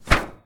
shield-hit-3.ogg